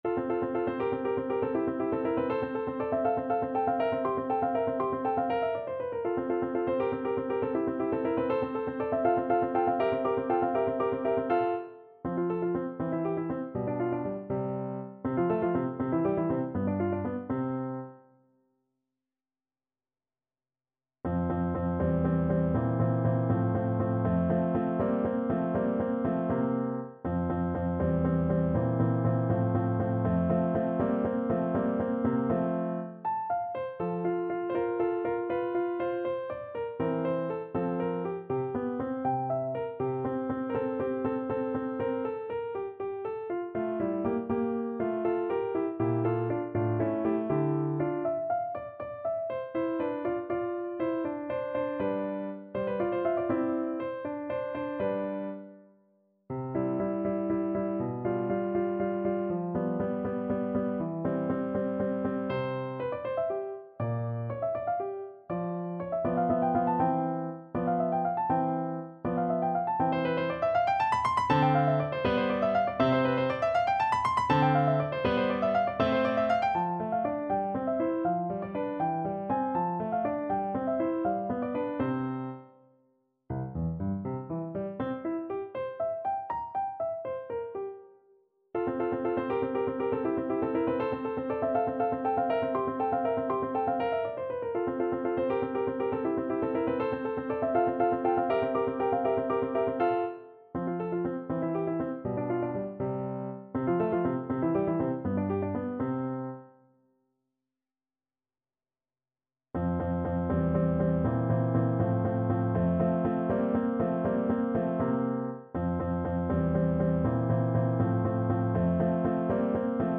~ = 100 Allegro (View more music marked Allegro)